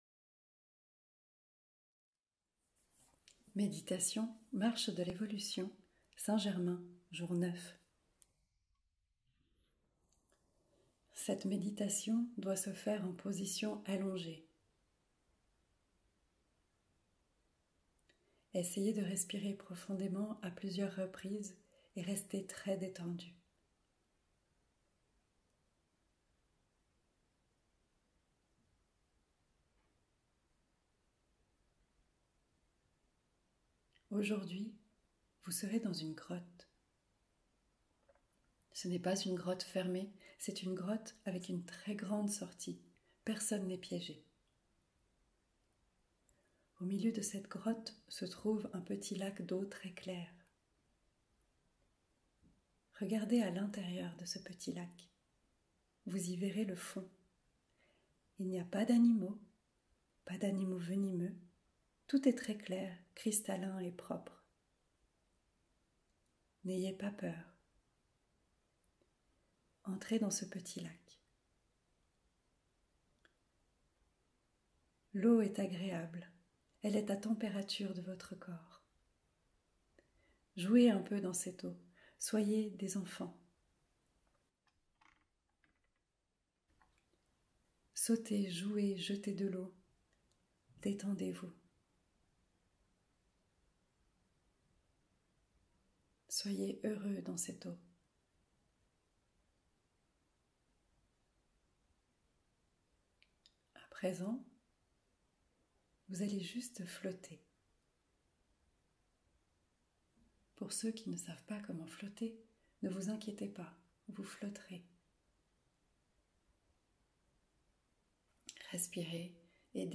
Méditation - sans_pub